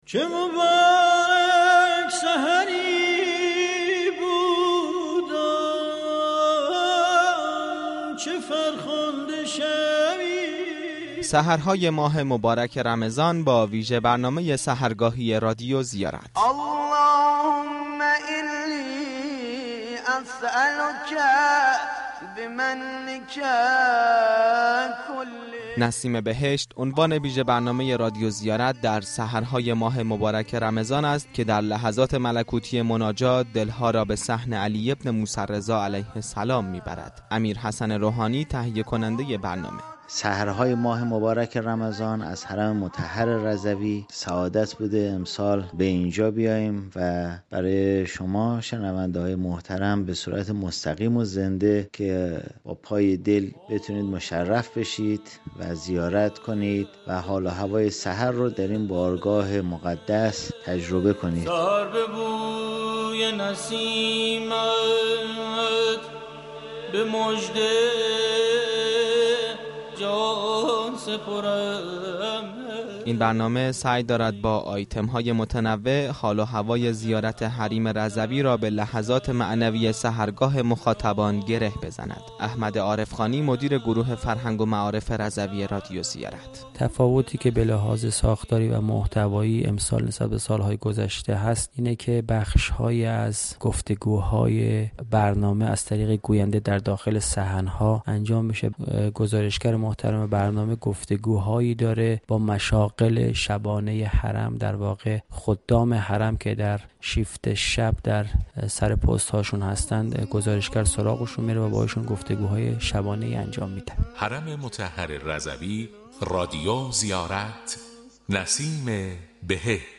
رادیو زیارت در ماه مبارك رمضان ویژه برنامه سحرگاهی خود با نام نسیم بهشت را از حرم رضوی به صورت زنده تقدیم مخاطبان می كند.